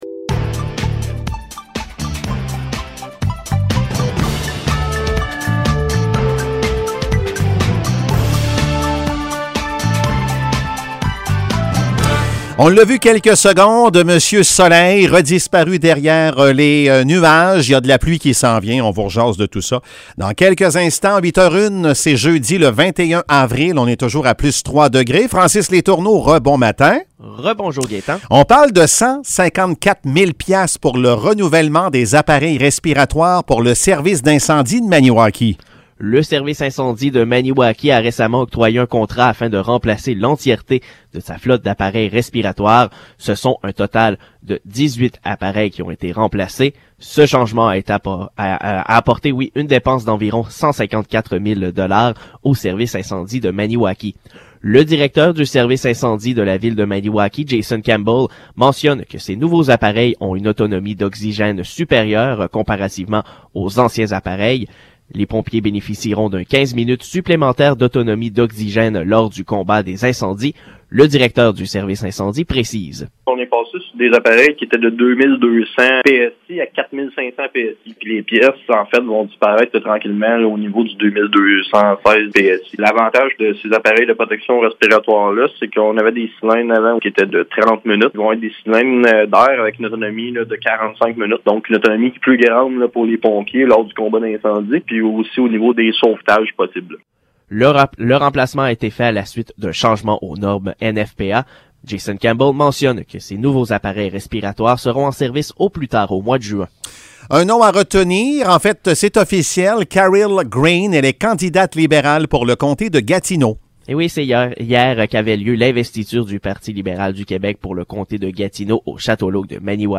Nouvelles locales - 21 avril 2022 - 8 h